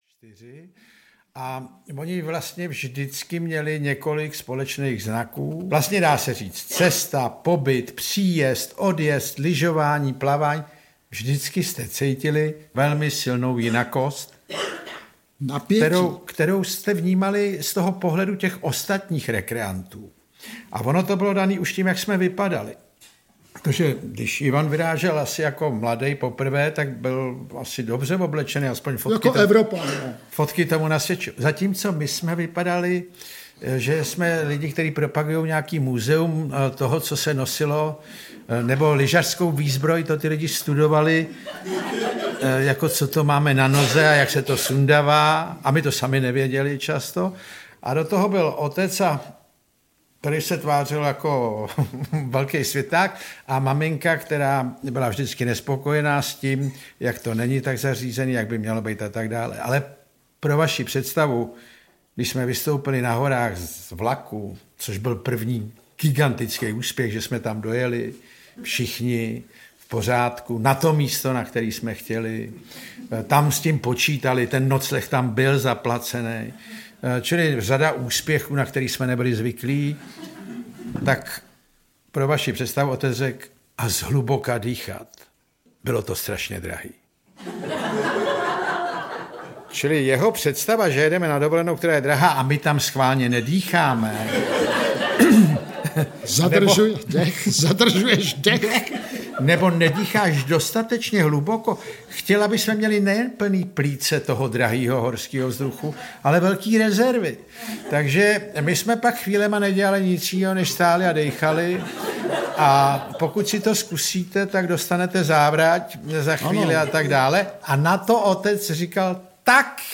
Romantická dovolená audiokniha
Bratři Krausovi čtou a vyprávějí o výletech a dovolené z dob, kdy sníh nebyl ještě umělý a kufry neměly kolečka…
Ukázka z knihy